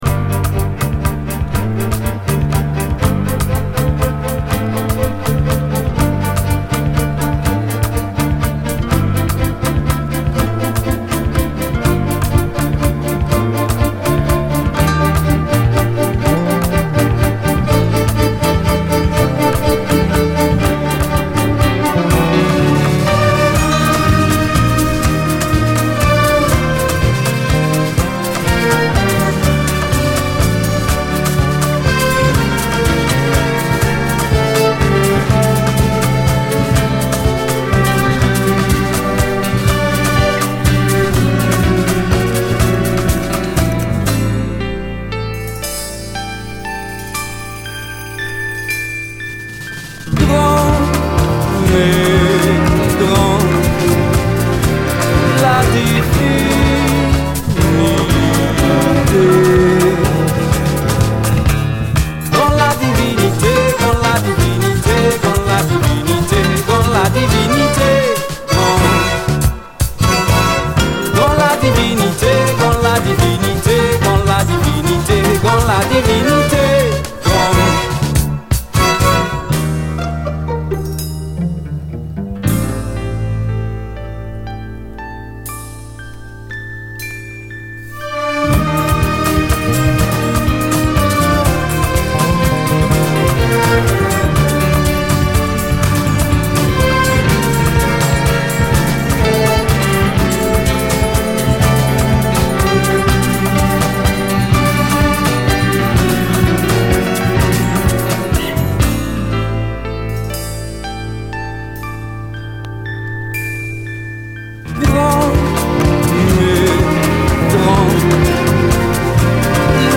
CARIBBEAN